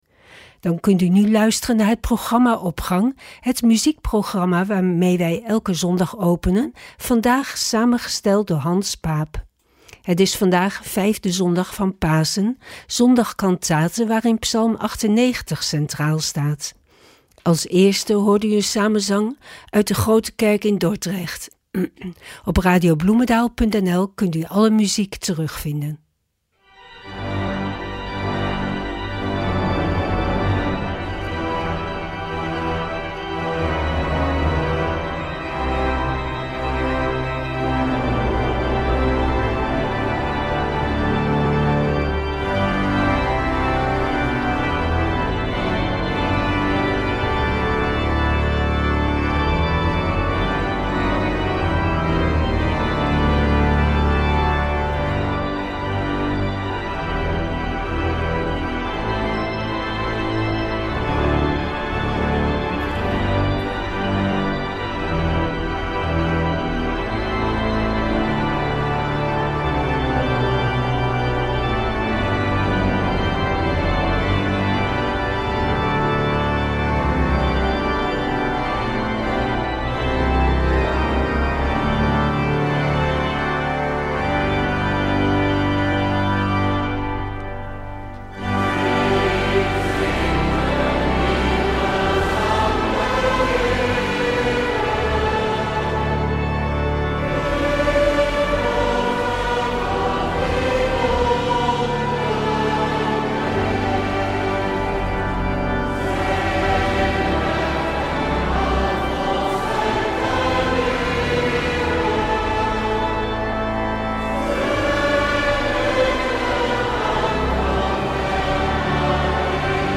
Opening van deze zondag met muziek, rechtstreeks vanuit onze studio.
Samenzang
Man nehme sich in acht (Men neme zich in acht, enz.); Uitgevoerd door het Amsterdam Baroque Orchestra & Choir o.l.v. Ton Koopman. 3) Psalm 98 Op. 91 (Singet dem Herrn ein neues Lied) van Felix Mendelssohn-Bartholdy (1809-1847) en daarvan drie gedeelten: 1.